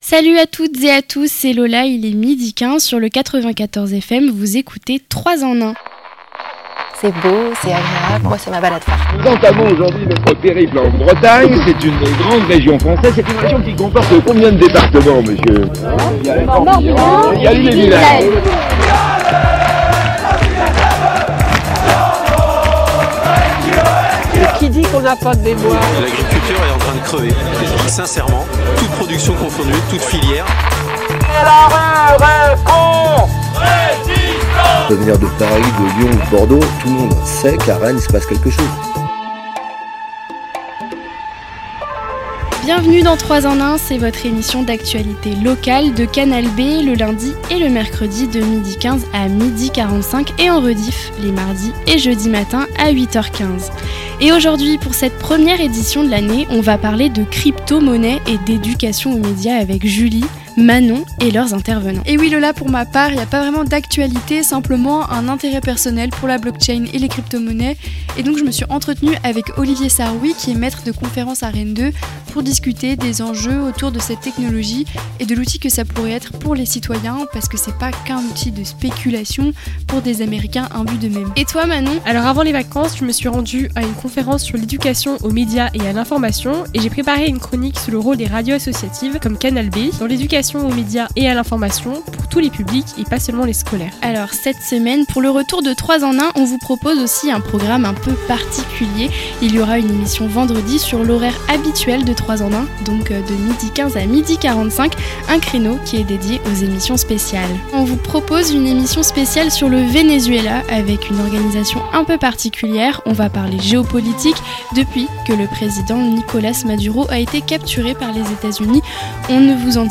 L'interview
La Chronique